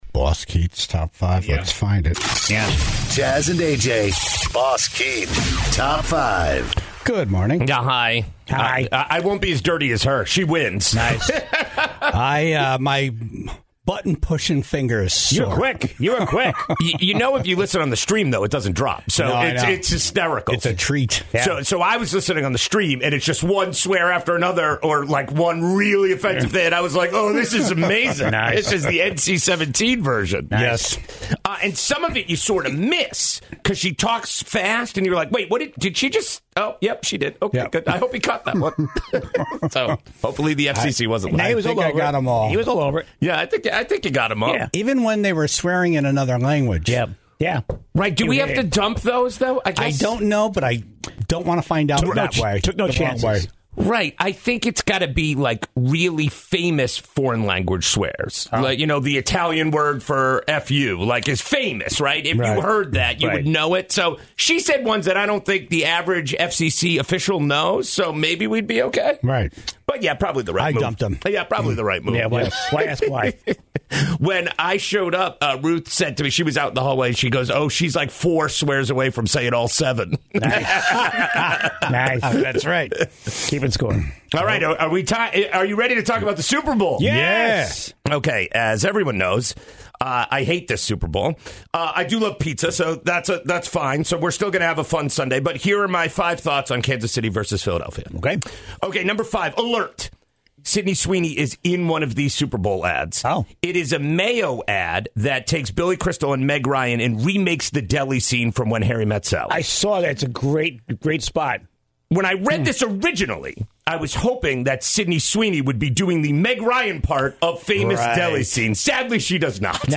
in studio